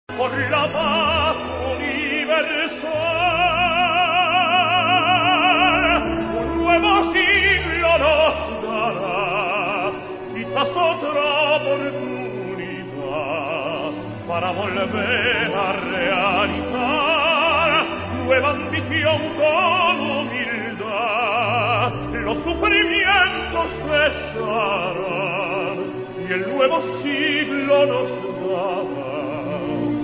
key: D-major